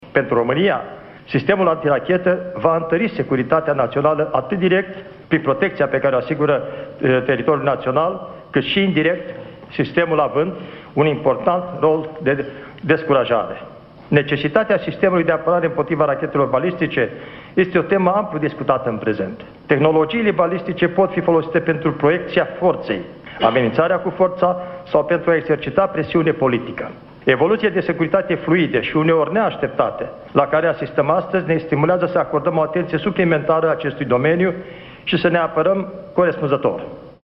În cadrul unei ceremonii de inaugurare, ministrul român de Externe, Lazăr Comănescu, a explicat că toată tehnica instalată la baza din județul Olt este funcțională.